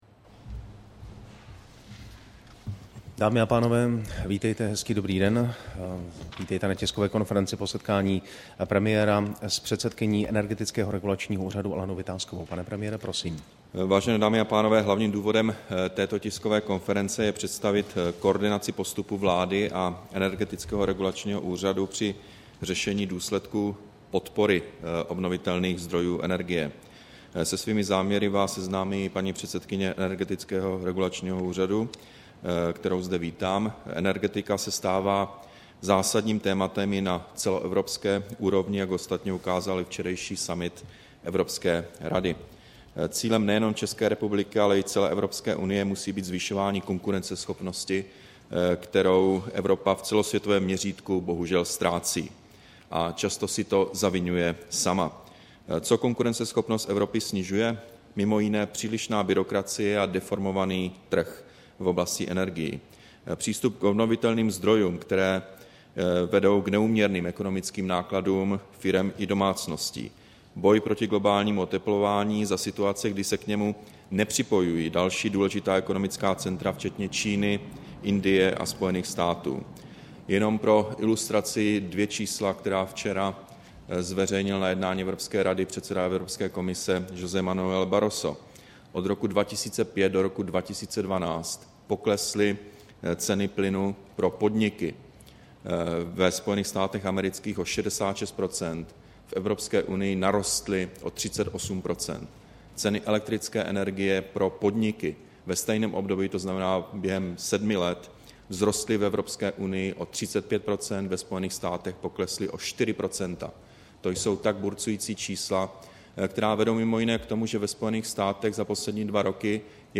Tisková konference po jednání premiéra s předsedkyní ERÚ, 23. května 2013